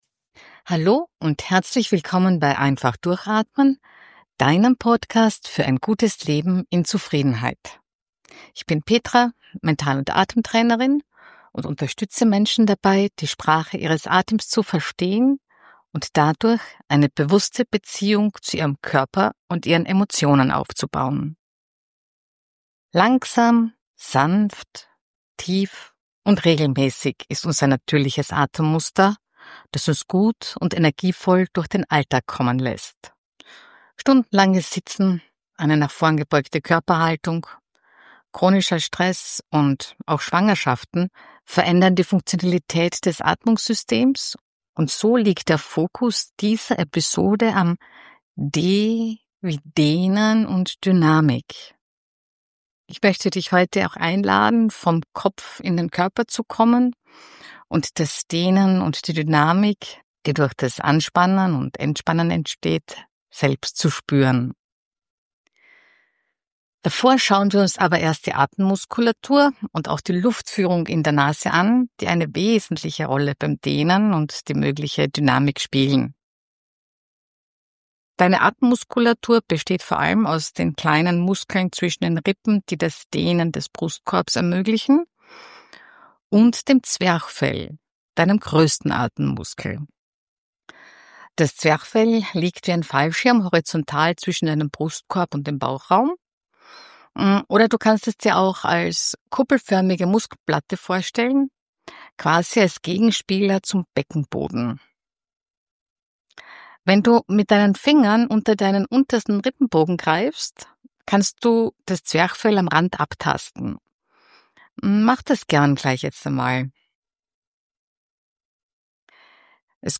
Du erfährst und wirst angeleitet durch Übungen: wie du dein Zwerchfell – deinen wichtigsten Atemmuskel – bewusst aktivierst, warum dein Brustkorb sich nicht nur hebt, sondern auch dehnen will, und wie du mit der Dreiecksatmung zur Ruhe kommst – beim Einschlafen oder mitten in der Nacht. Ich begleite dich mit sanften Impulsen raus aus dem Kopf, rein in den Körper.